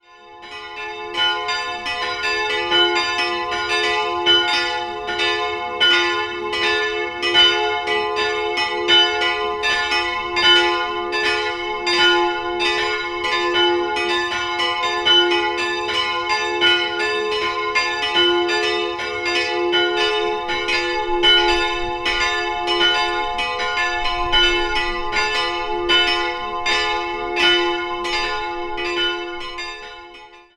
3-stimmiges TeDeum-Geläute: fis''-a''-h'' Die beiden größeren Glocken wurden im Jahr 1949 von der Gießerei Petit&Edelbrock in Gescher gegossen. Bei der kleinen handelt sich noch um ein Exemplar aus der Zeit um 1500.